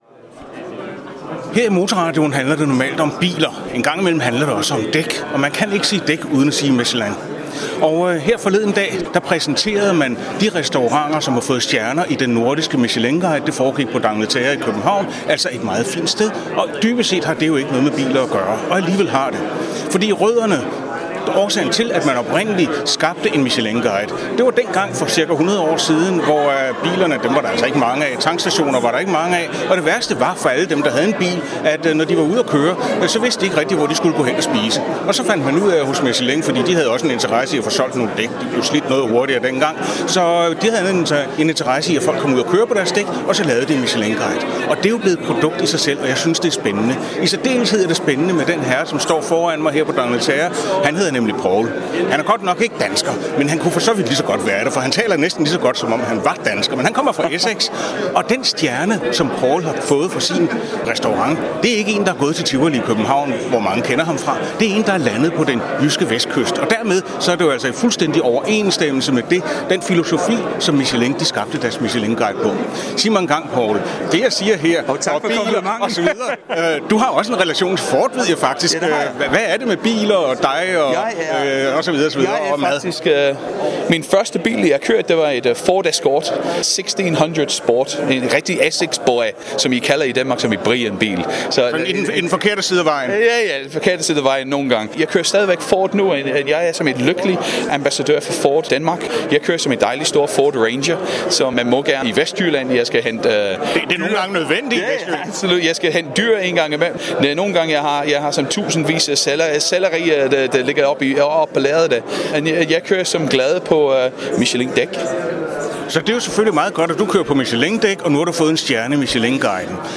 Vi fik en hurtig, underholdende og meget improviseret snak med den festlige kok, som har sin rod i Essex i Storbritannien men har boet i Danmark i 21 år, også kendt for sin "The Paul" i Tivoli.
Interview med Paul Cunningham i Motorradioen